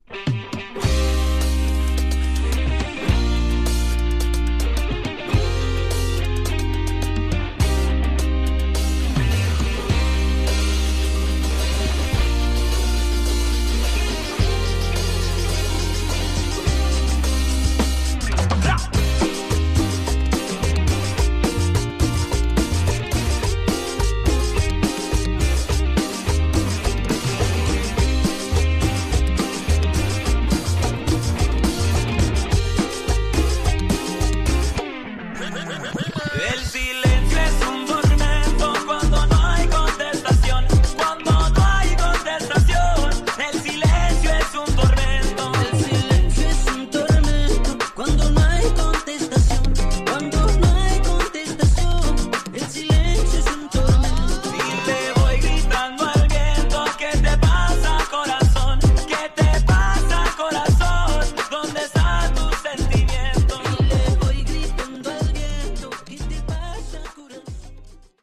Tags: Cumbia
Super bailables: cumbia con raps, rock, housito.